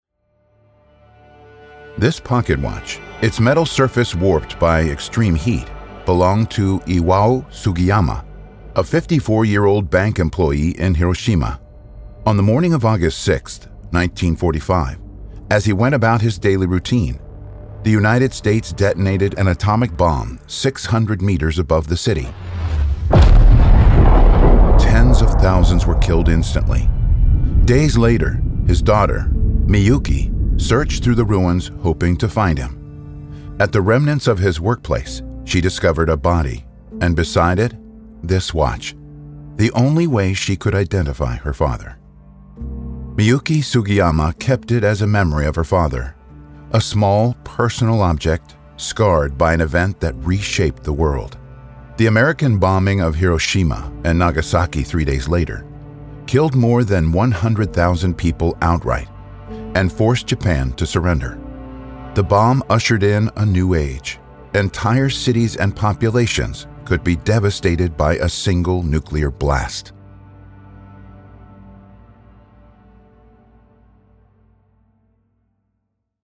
• Podcast/Audioguías